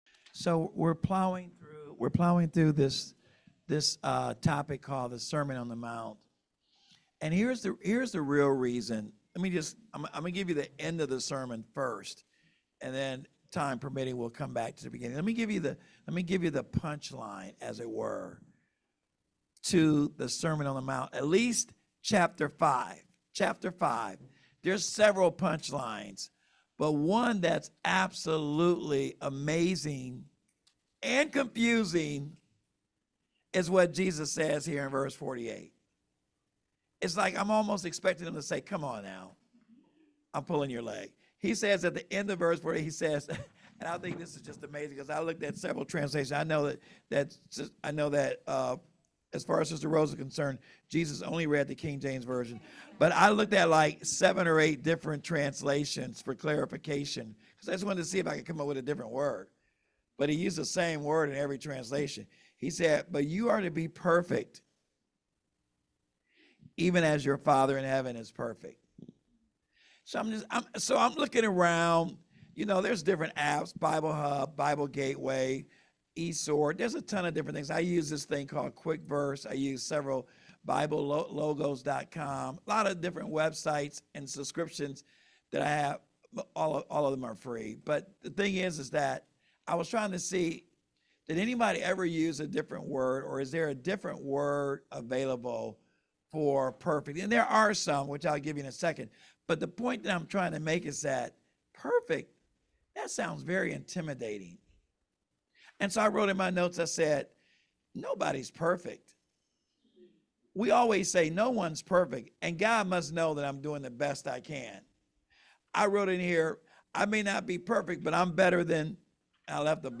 Sermon Handout Scripture(s